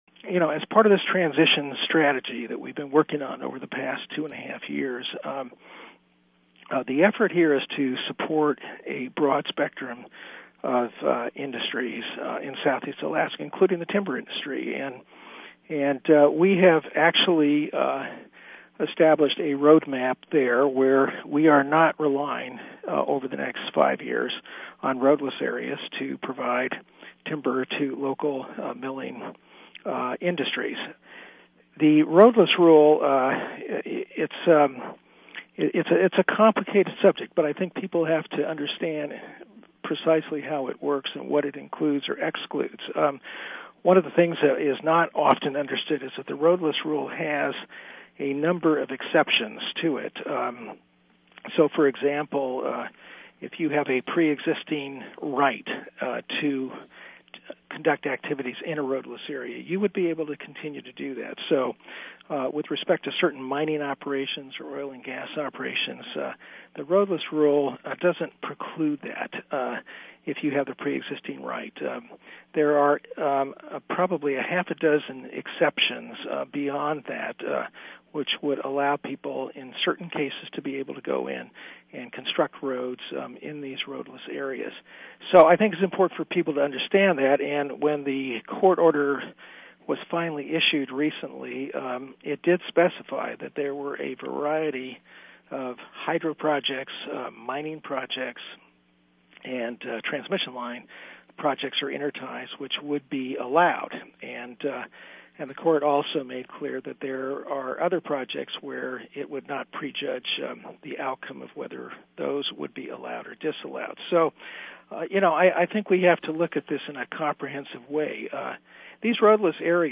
During a recent wide-ranging interview with Undersecretary Sherman, we asked him to comment on the Department’s legal plans since the Roadless Rule exemption was lifted for Alaska’s Tongass National Forest.